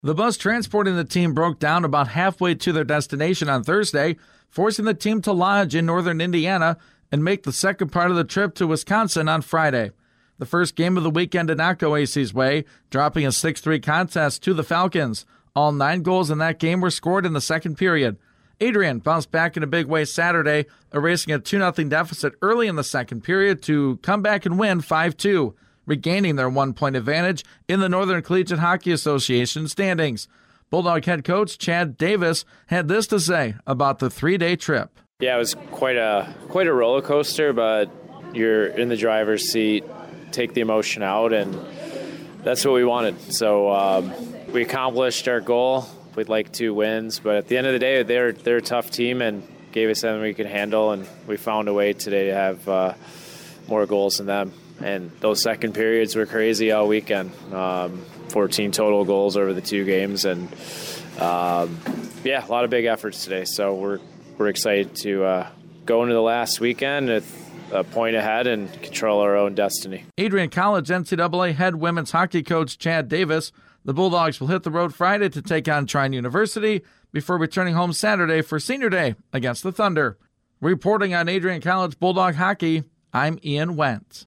nws012-hockey_recap.mp3